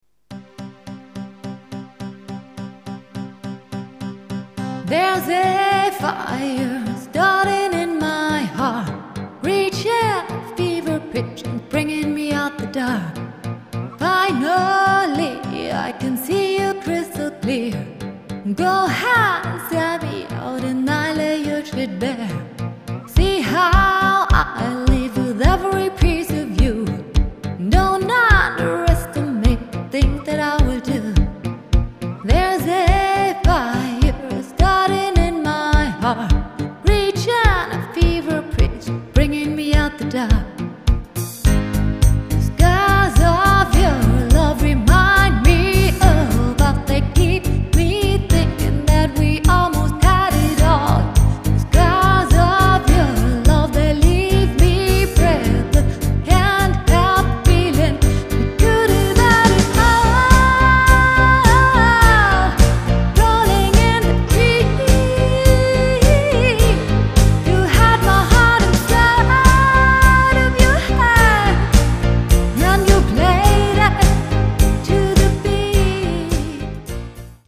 Tanz-& Unterhaltungskapelle